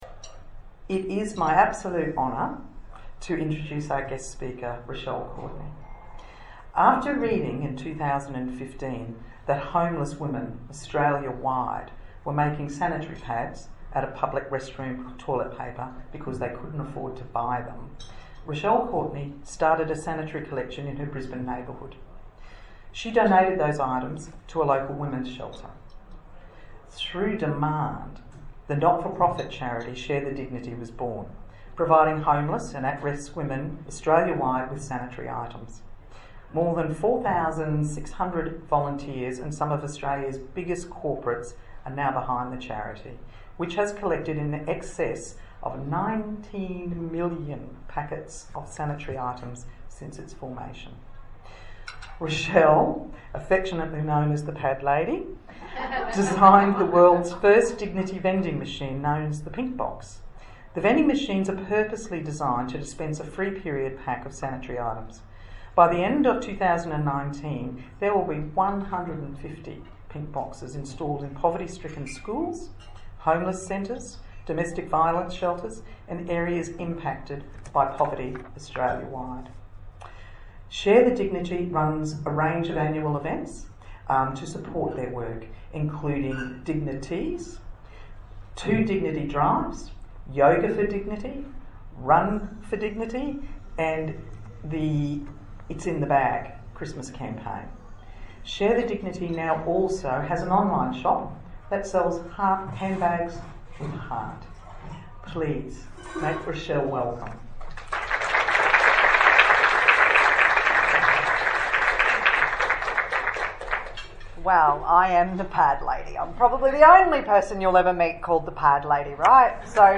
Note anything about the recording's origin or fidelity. Office Support: 2019 Christmas Lunch